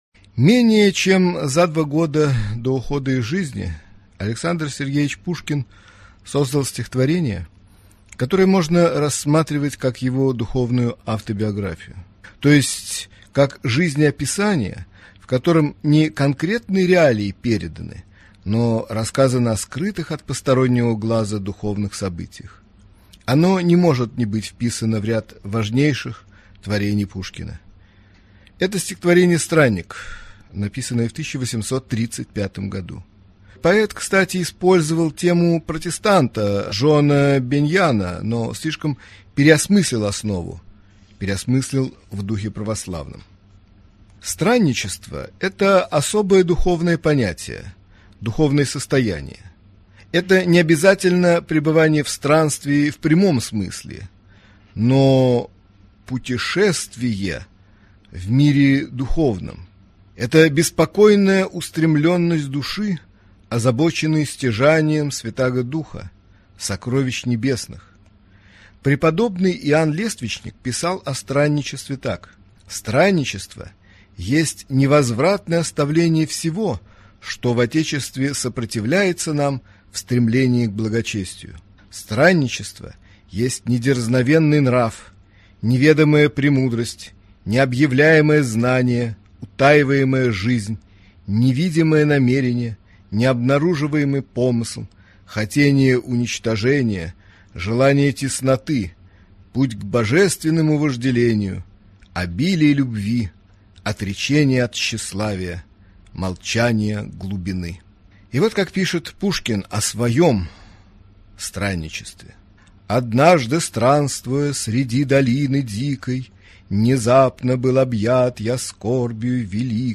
Аудиокнига Лекция